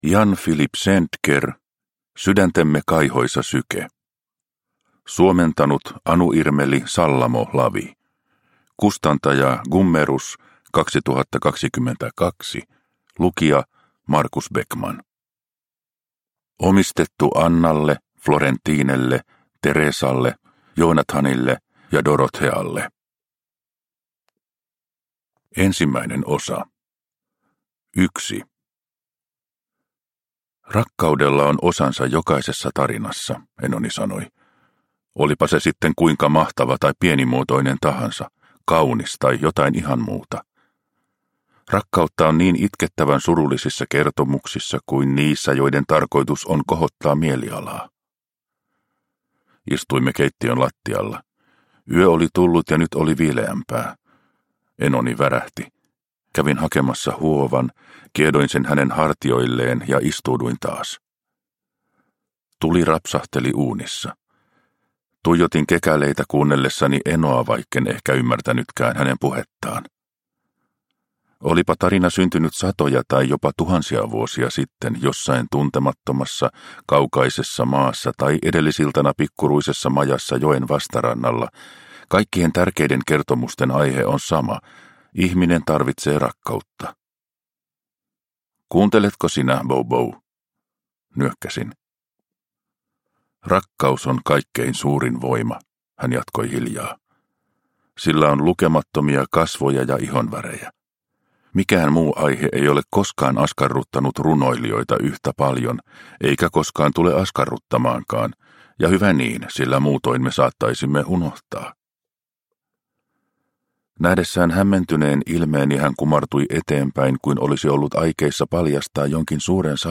Sydäntemme kaihoisa syke – Ljudbok – Laddas ner